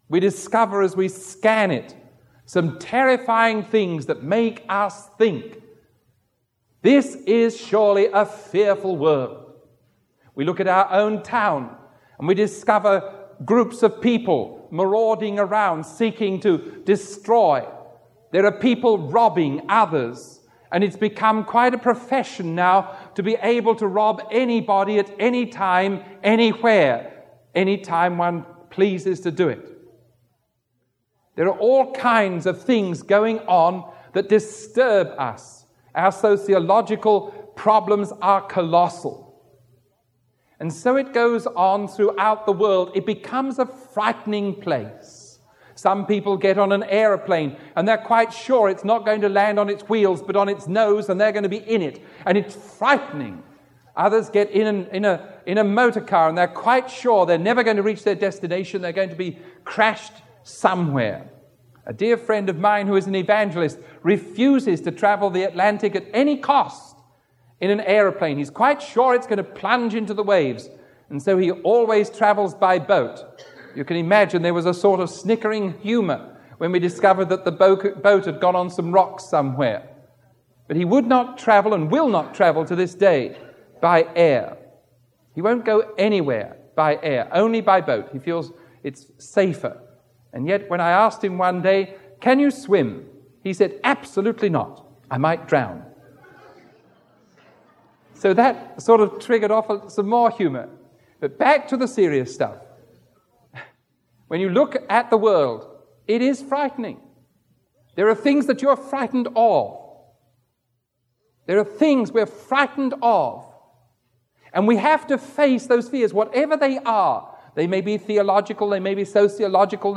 He uses vivid imagery to contrast the “idols” that people often rely upon—such as patriotism, wealth, or knowledge—with the enduring power of God’s promise. Throughout the sermon, he repeatedly urges his listeners to trust in God’s love and unwavering support, exemplified by the sacrifice of Jesus Christ, and to find true peace in a relationship with him. The sermon ends with a prayer for the congregation to embrace the profound message of hope and strength found in God’s word.